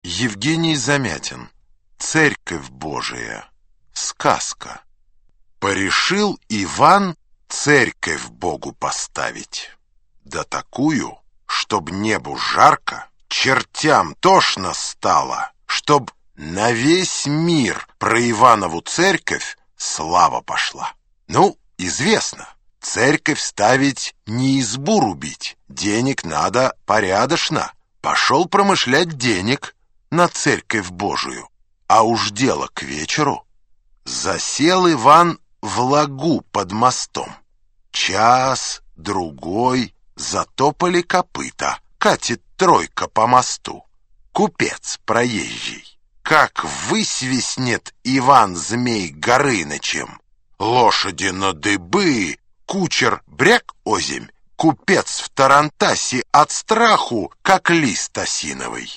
Аудиокнига Классика русского рассказа № 4 | Библиотека аудиокниг
Aудиокнига Классика русского рассказа № 4 Автор Сборник Читает аудиокнигу Александр Клюквин.